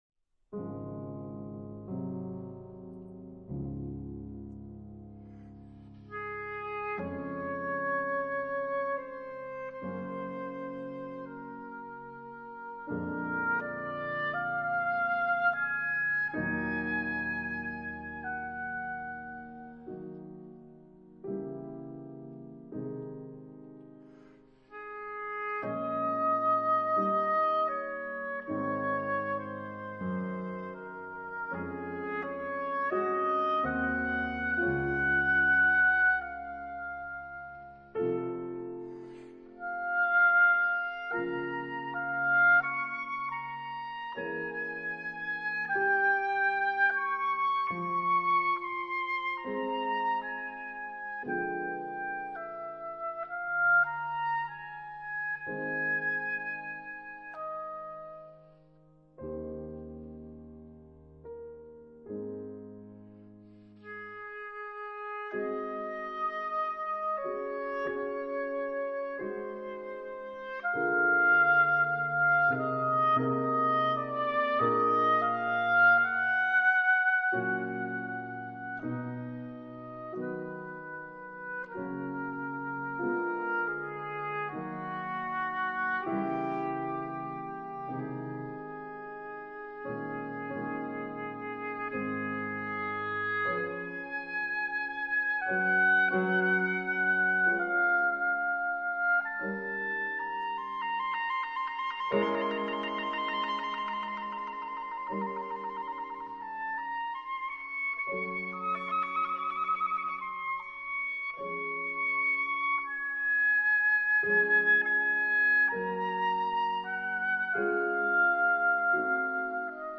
但在電腦上聽了後，發現雙簧管的音色實在美極了。
的確，這支雙簧管果然音色極優。